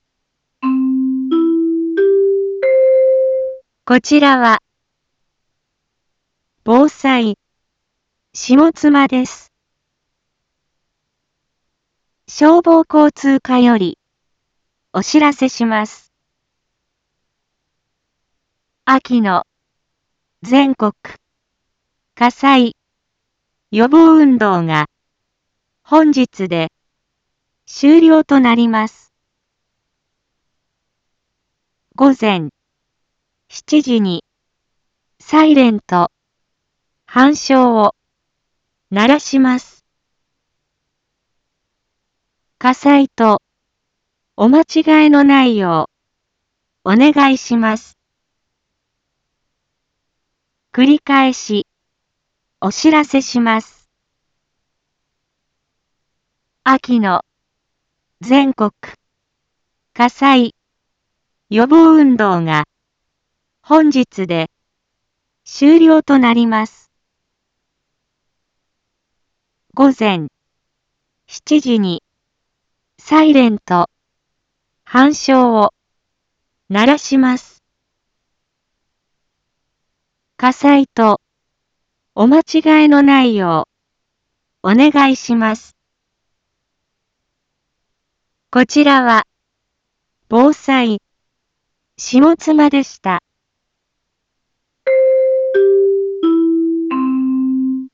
一般放送情報
Back Home 一般放送情報 音声放送 再生 一般放送情報 登録日時：2022-11-15 06:46:42 タイトル：秋季全国火災予防運動に伴うサイレンについ インフォメーション：こちらは、防災、下妻です。